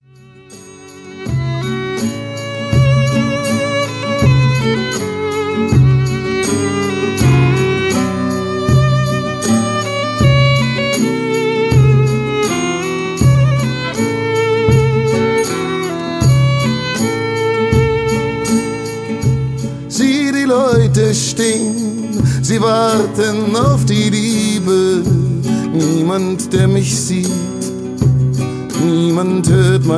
World Music From Berlin